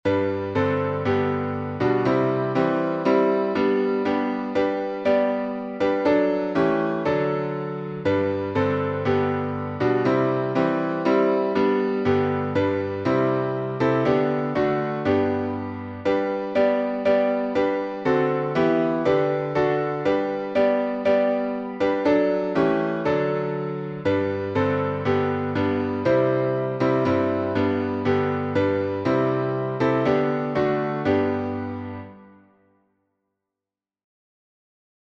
#5103: Tis So Sweet to Trust in Jesus — G major | Mobile Hymns